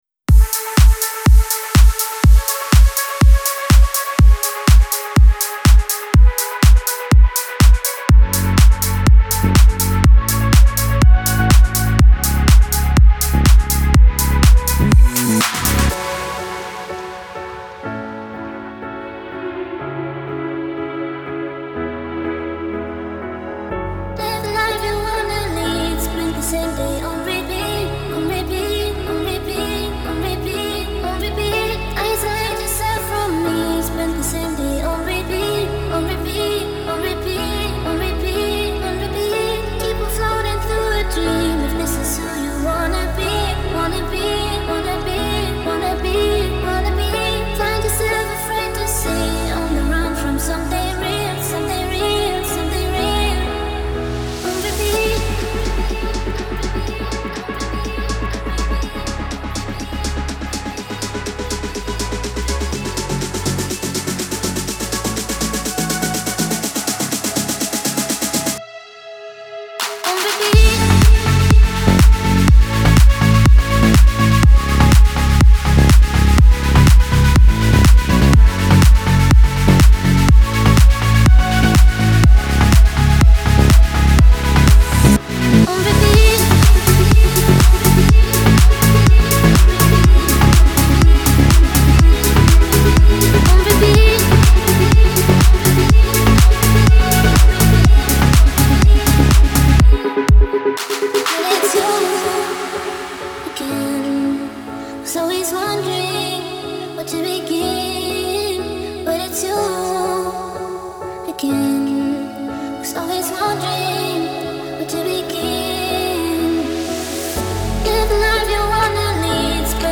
Genre : Electro, Alternative